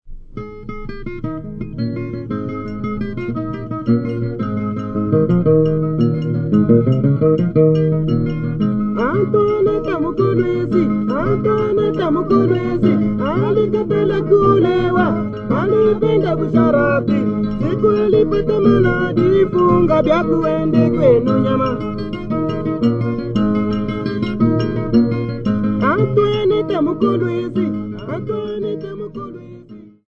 Folk music--Africa
Field recordings
sound recording-musical
Indigenous topical folk song with singing accompanied by 2 guitars and the sound of a struck bottle.